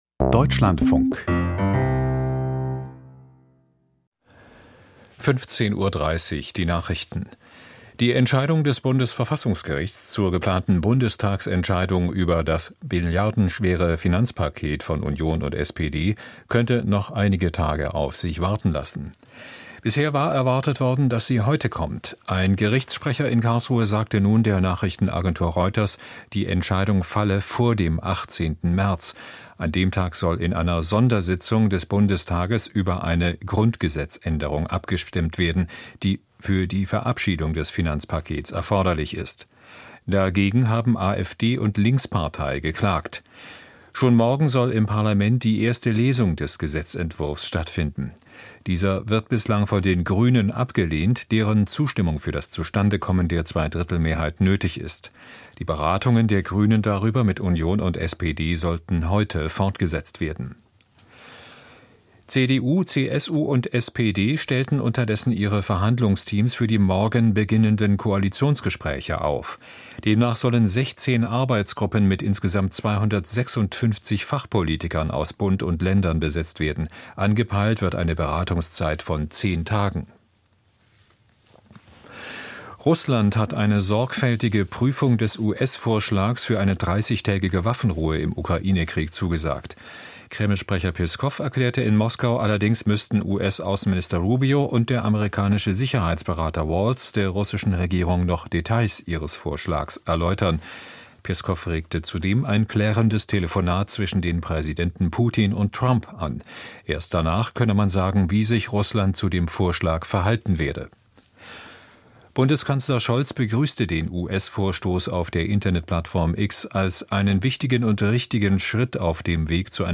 Die Deutschlandfunk-Nachrichten vom 12.03.2025, 15:30 Uhr